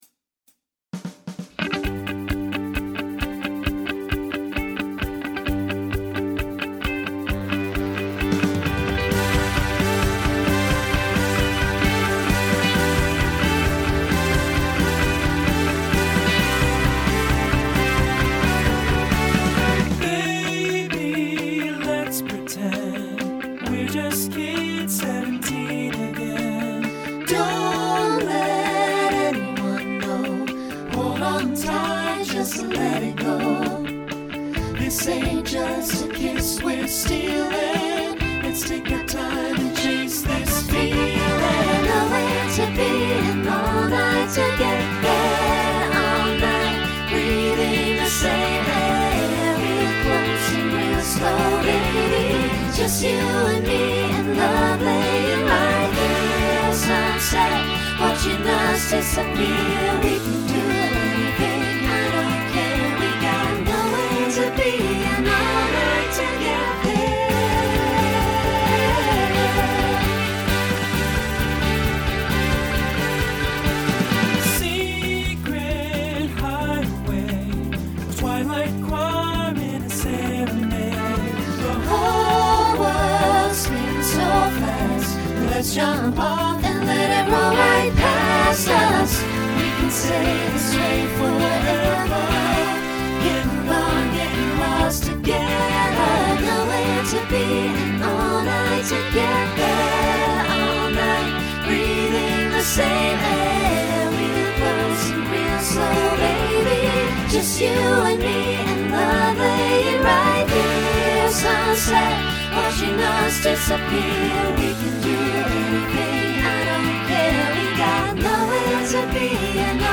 Voicing SATB Instrumental combo Genre Country
Mid-tempo